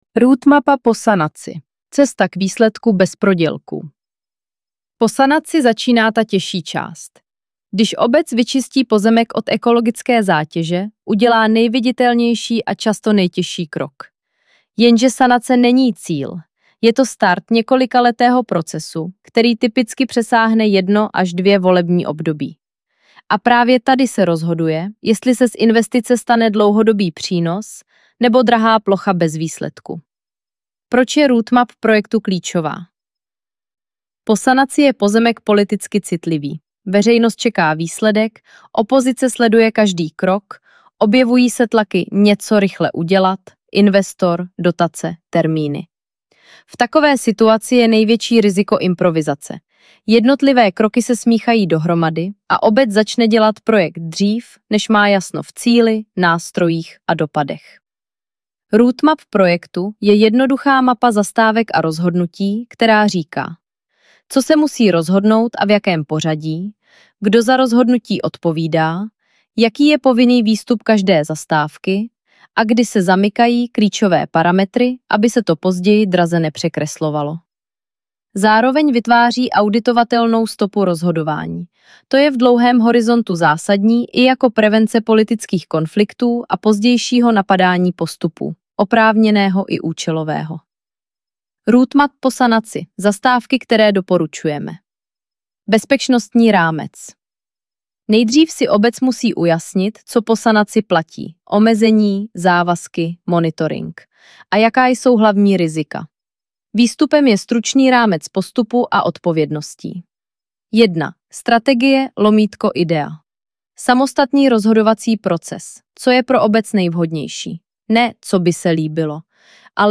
Audio verze článku – vhodné na poslech při rutinní práci.